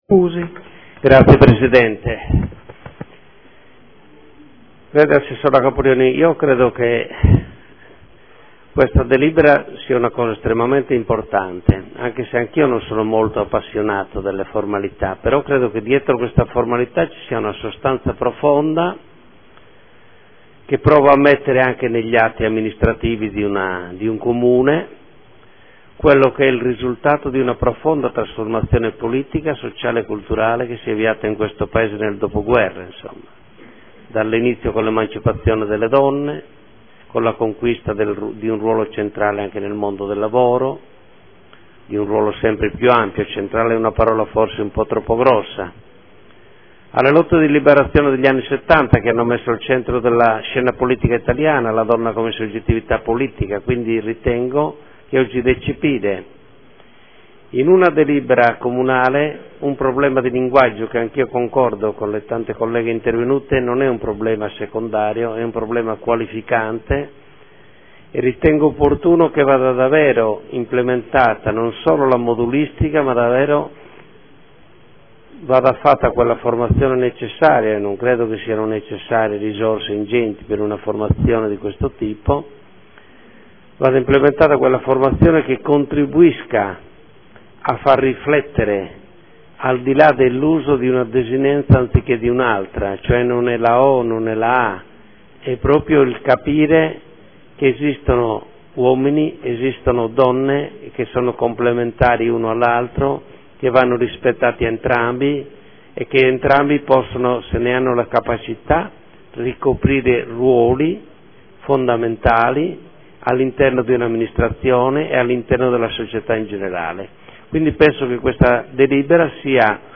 Marco Cugusi — Sito Audio Consiglio Comunale